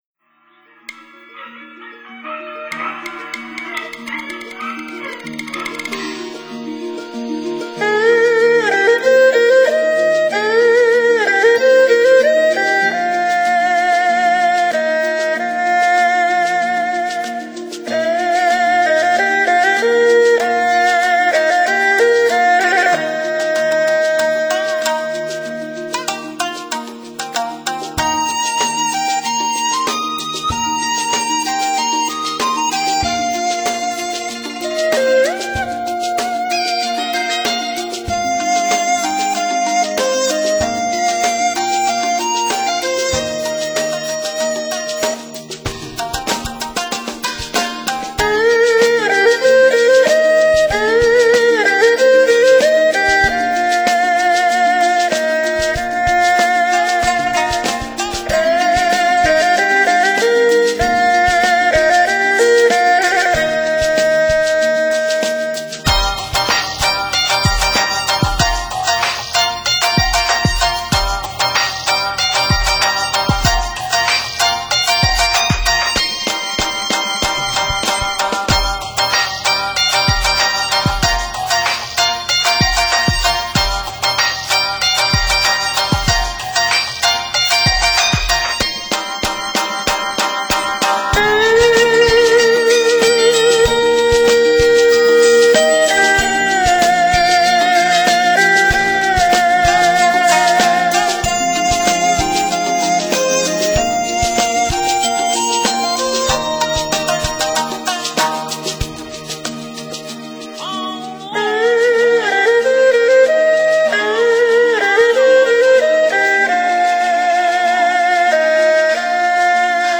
中国原创地理音乐
琵琶
笛/箫
古琴
二胡
三弦